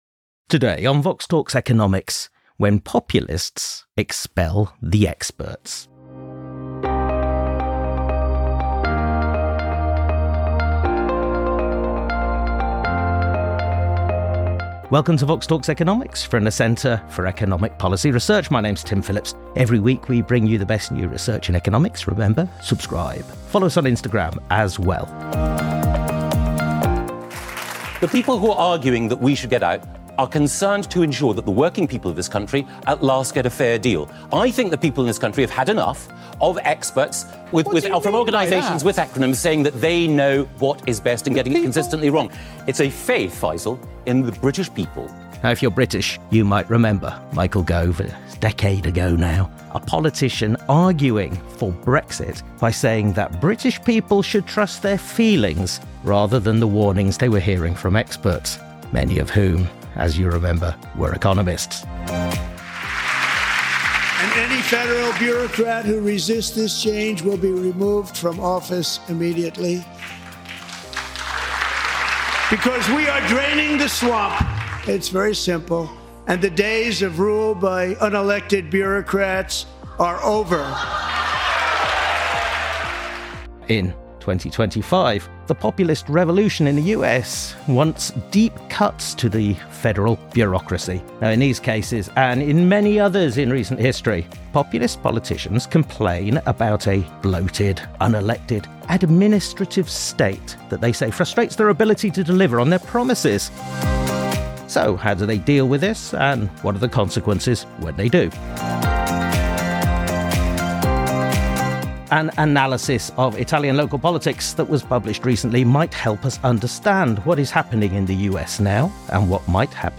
Recorded at the CEPR Paris Symposium.